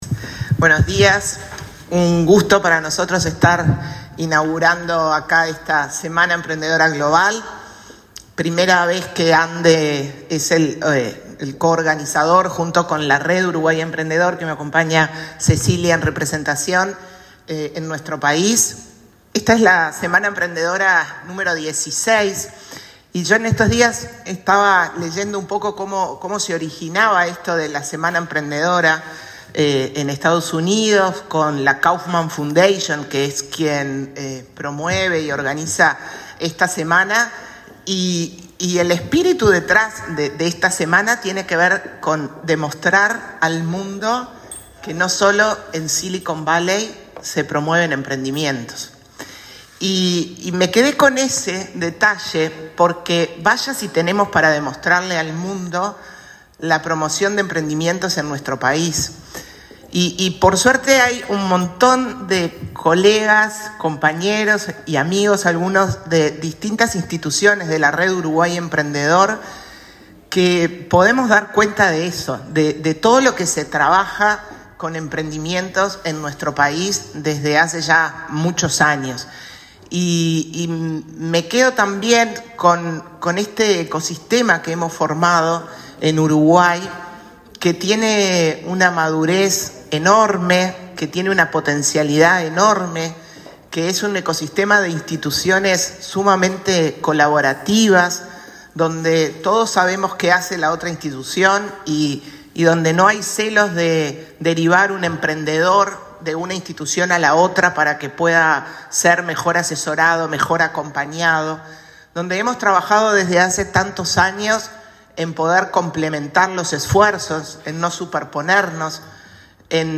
Palabras de la presidenta de ANDE, Carmen Sánchez
Este lunes 13 en Montevideo, la presidenta de la Agencia Nacional de Desarrollo (ANDE), Carmen Sánchez, participó de la apertura de la Semana